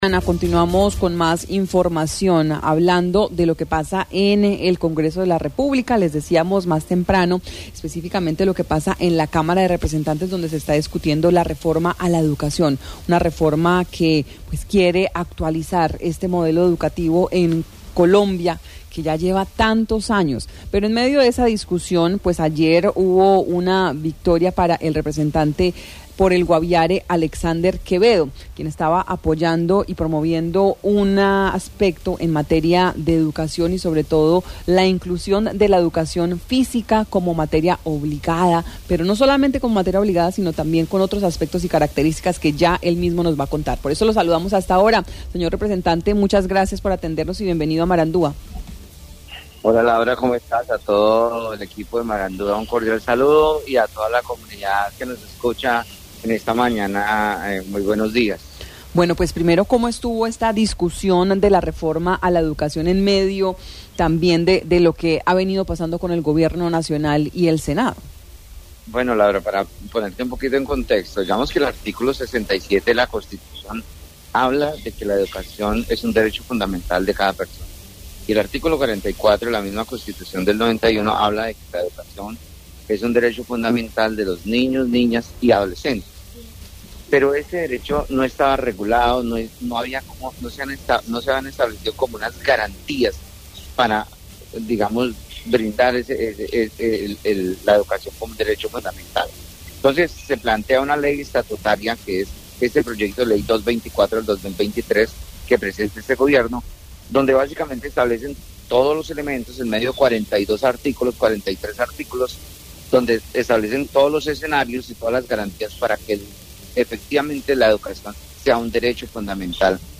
El representante a la Cámara del Guaviare, Alexander Quevedo, habló en Marandua Noticias sobre la reforma de la educación y la aprobación en plenaria respecto a la propuesta para que la educación física en primaria sea impartida exclusivamente por profesionales certificados.